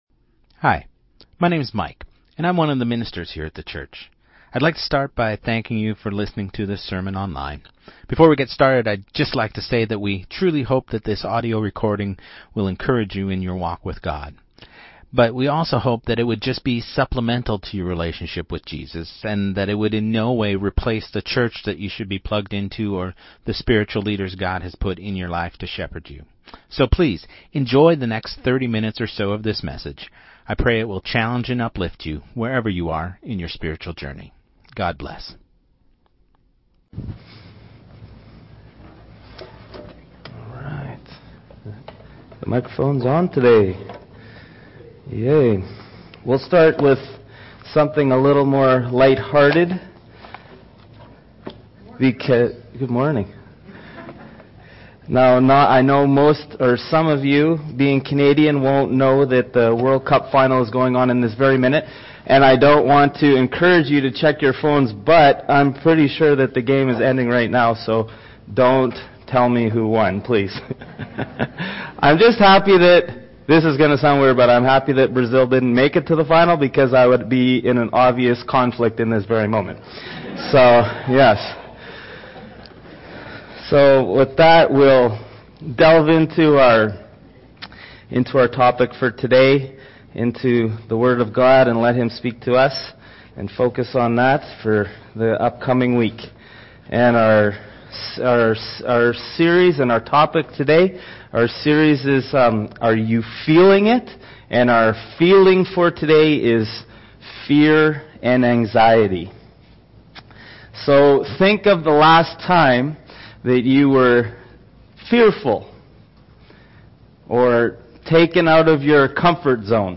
Sermon Series | Church of Christ Saskatoon